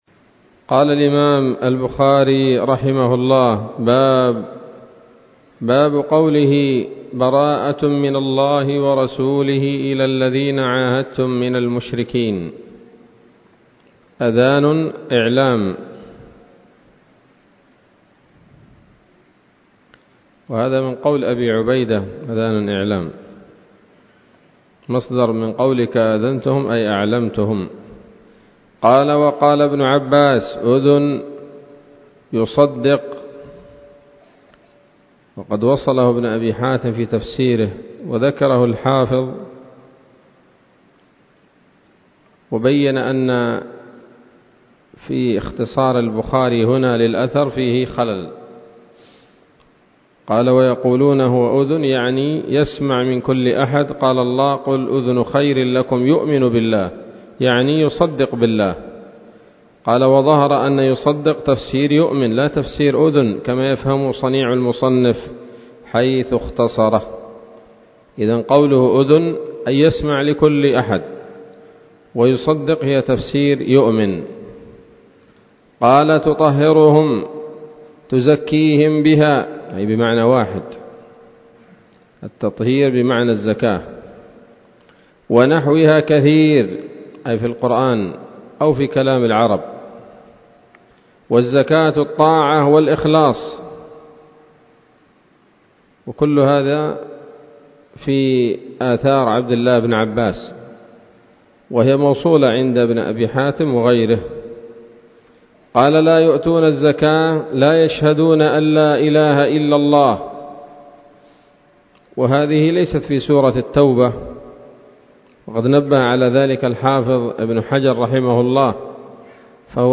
الدرس الثامن عشر بعد المائة من كتاب التفسير من صحيح الإمام البخاري